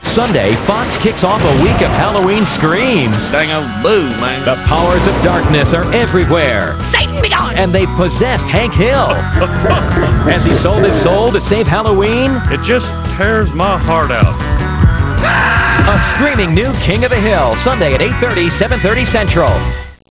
MIKE JUDGE as Hank Hill
SALLY FIELD as Junie Harper
koh20610.mov (818k, Quicktime)   Audio Promo